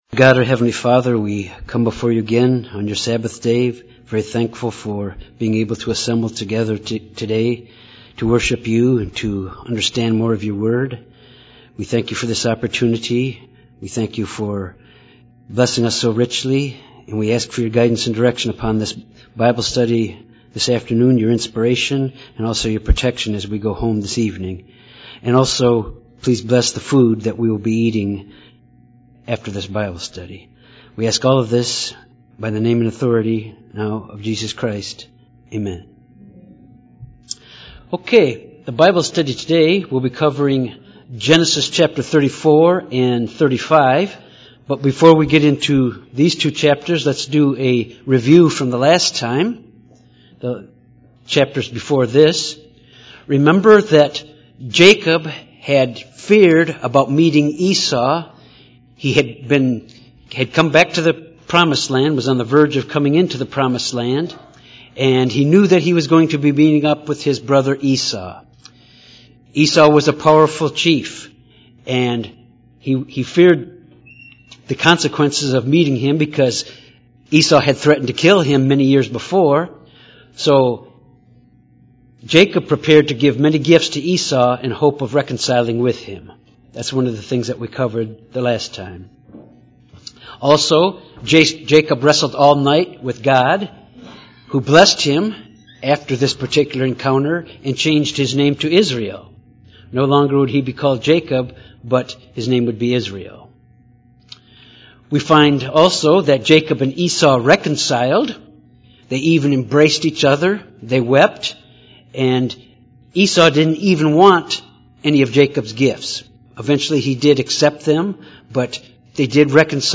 This Bible study covers Genesis 34-35. After Jacob’s daughter was raped, she was avenged by her brothers. Jacob moves to Bethel where Rachel dies in childbirth, and the death of Isaac.